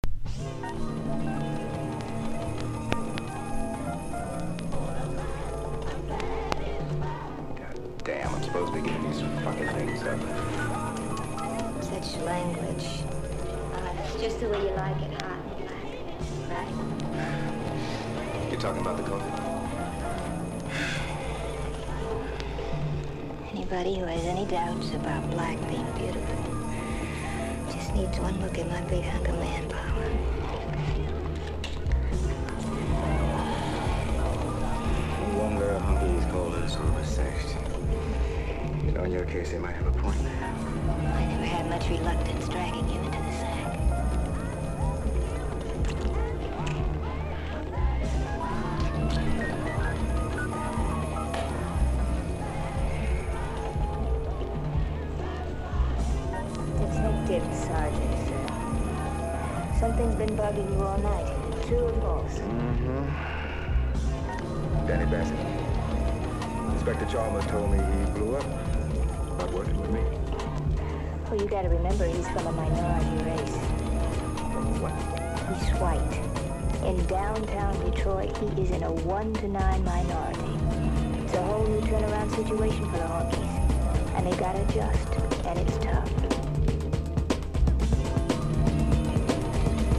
DEEP HOUSE / EARLY HOUSE# TECHNO / DETROIT / CHICAGO